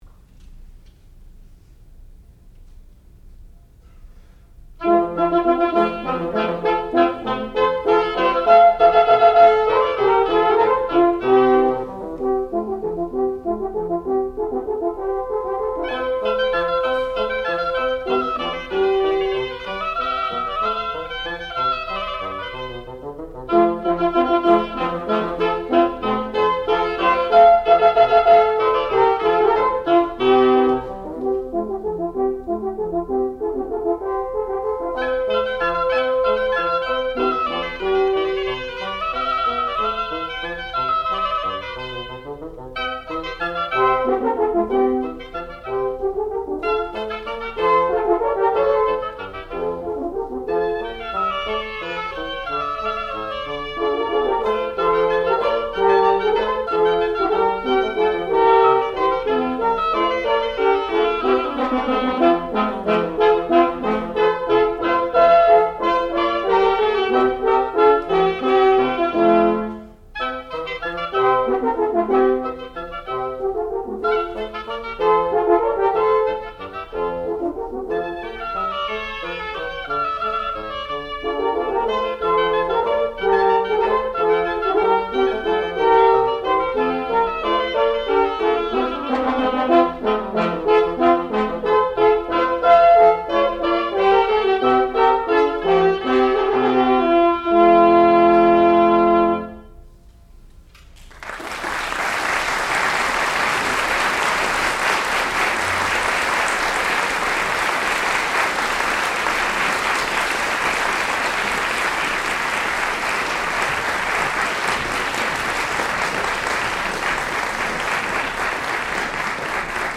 sound recording-musical
classical music
The Shepherd Chamber Players (performer).